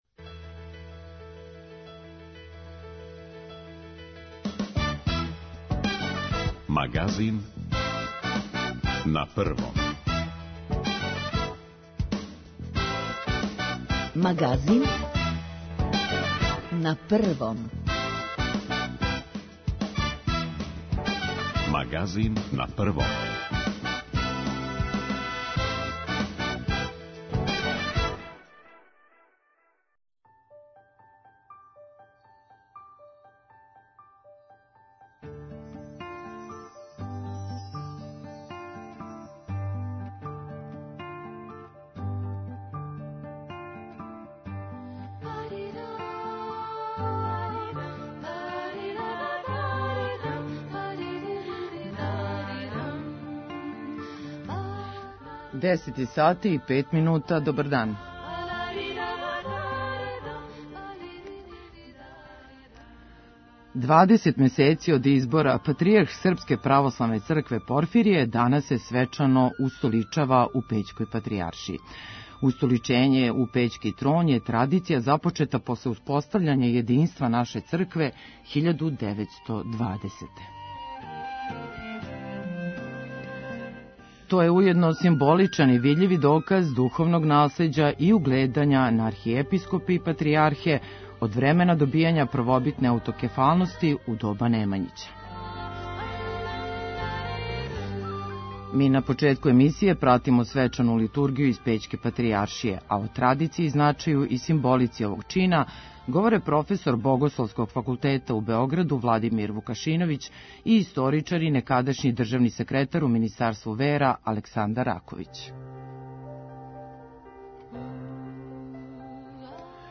На почетку емисије пратимо свечану литургију из Пећке патријаршије.